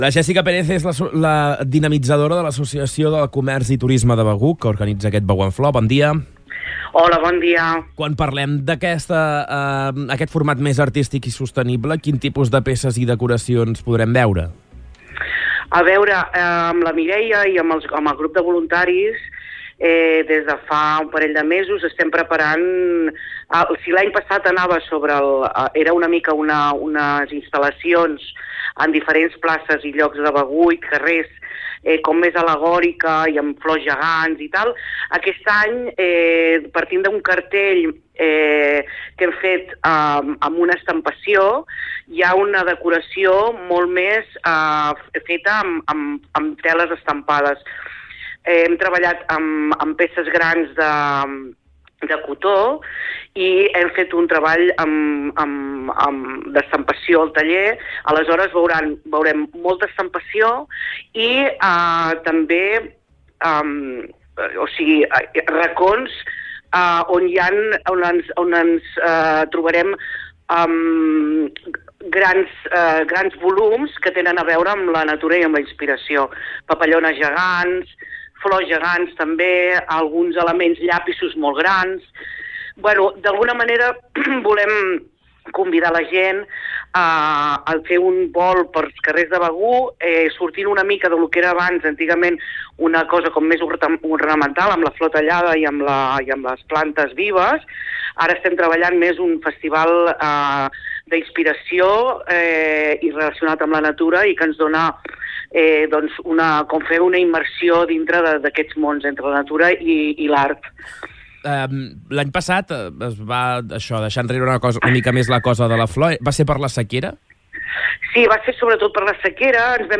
entrevista_BegurEnFlor.mp3